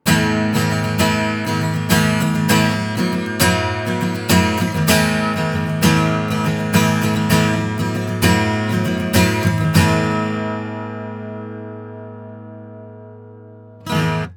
音質は、高音にかなり味付けがされていて、
パッと聴いた感じはパキッと派手なキャラクターで
ただ、やはり高音がシャリつく感があるので録るものによっては全然合わない場合もあるかと思います。
実際の録り音
アコースティックギター（ストローク）
NT2A-アコギ.wav